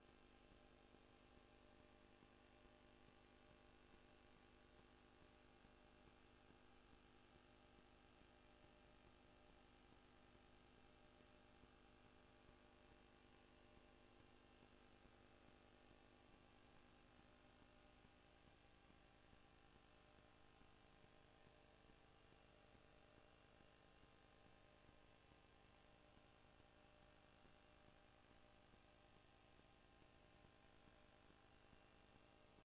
voorzetting raadsvergadering 16 september 2025